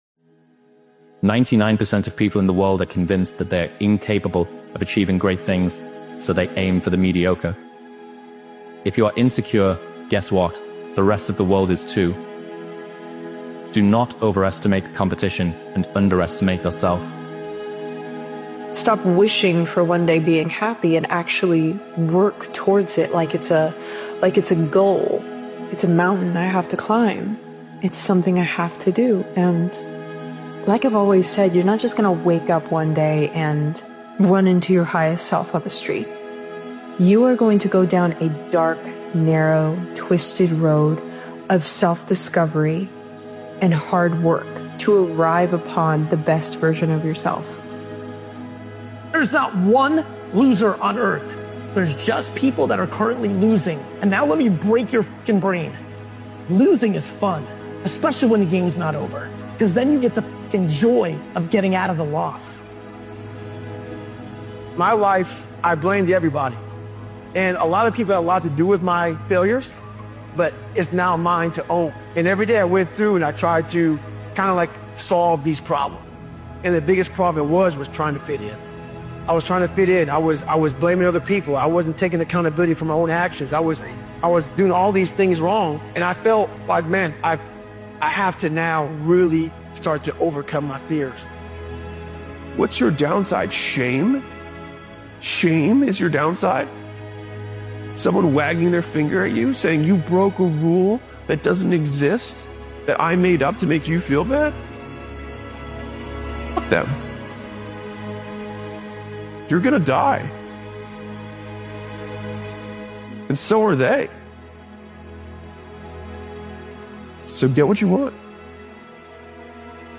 I WILL BECOME A CHAMPION ONE DAY – Powerful Motivational Speech episode is a determined and high-energy motivational epiosde created and edited by Daily Motivations. This powerful motivational speeches compilation is a declaration of belief, resilience, and purpose. It’s about staying locked in on your vision even when the odds are stacked against you.